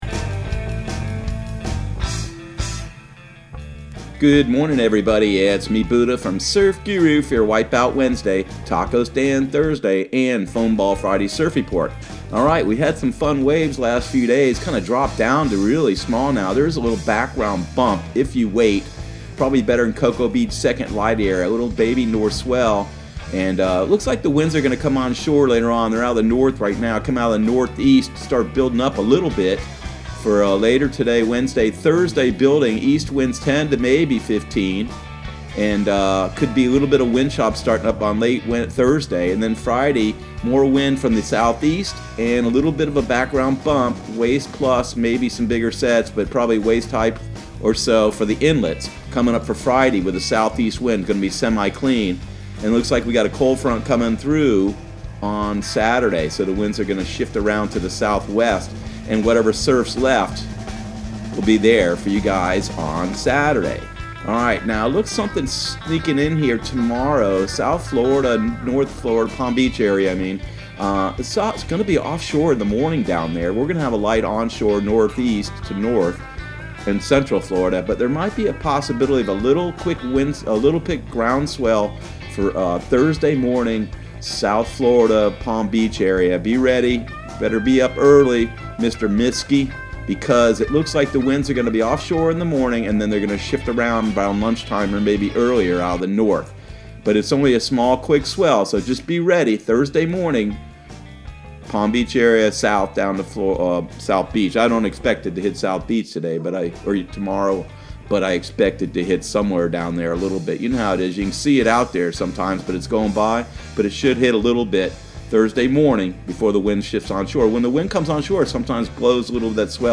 Surf Guru Surf Report and Forecast 01/29/2020 Audio surf report and surf forecast on January 29 for Central Florida and the Southeast.